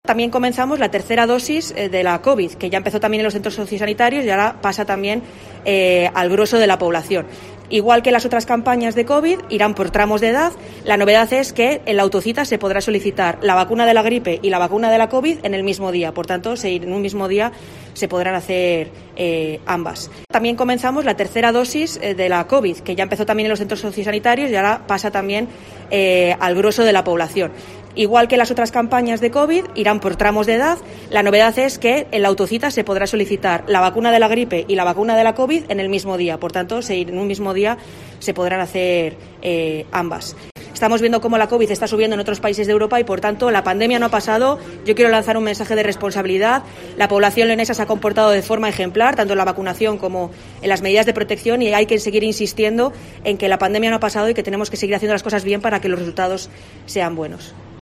Escucha aquí las palabras de la delegada territorial de la Junta en la provincia de León, Ester Muñoz